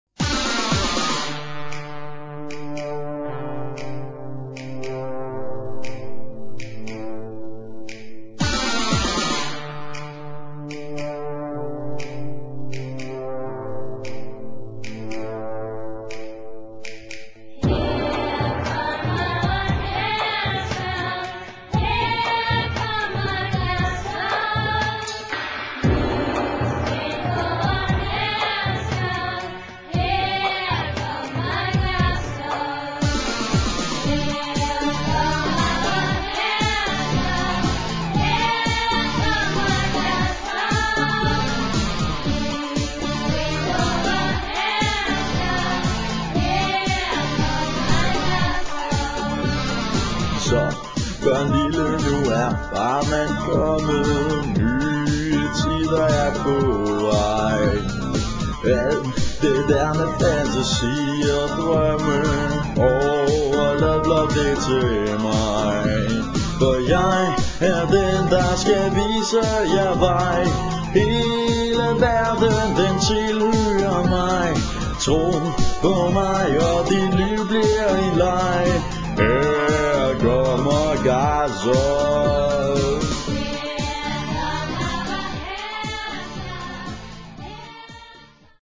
Lutter sangbare og ørehængende slagere finder man her!